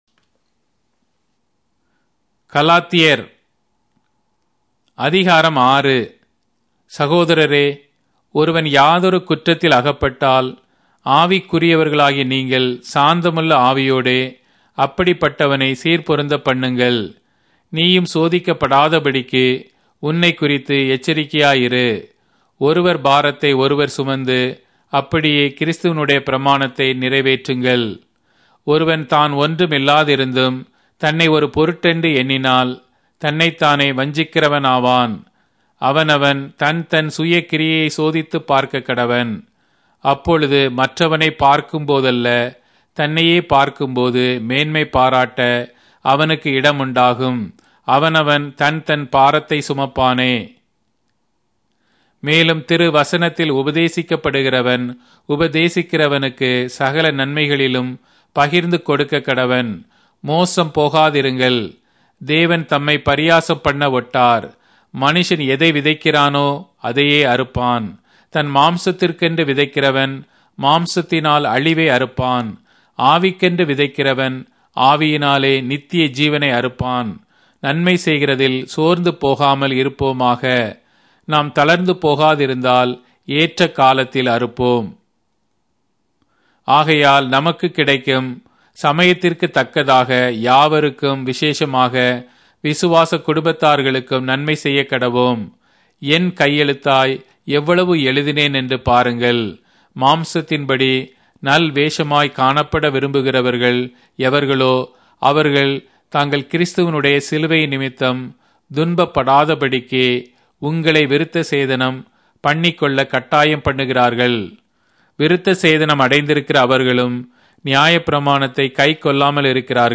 Tamil Audio Bible - Galatians 4 in Bnv bible version